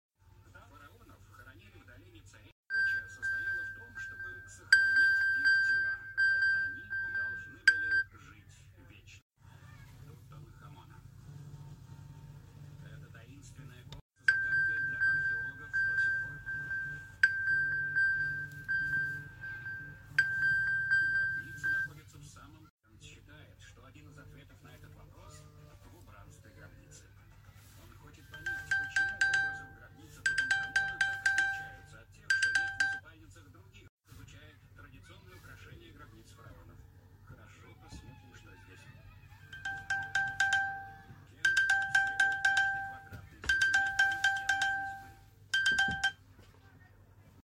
The Apples Find My Device sound effects free download
The Apples Find My Device Sound Between iOS 14 to 17